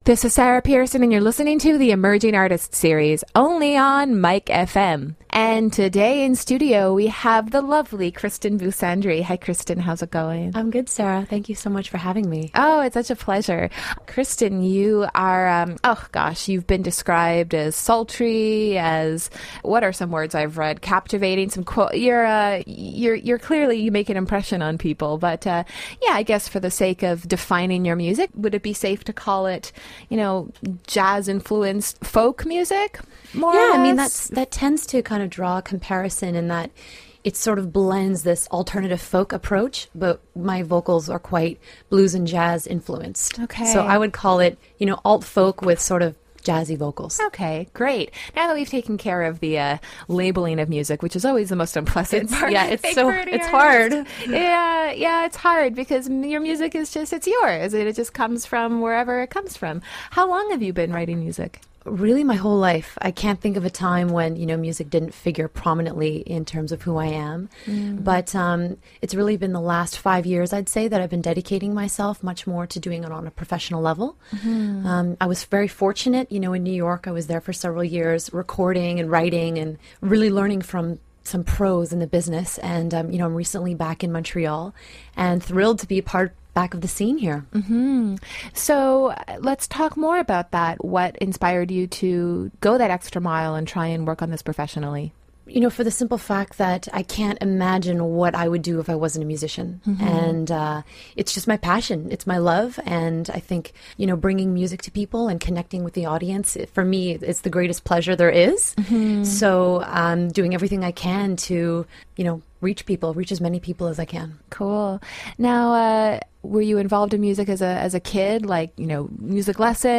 MikeFM Radio Interview on July 2nd, 2010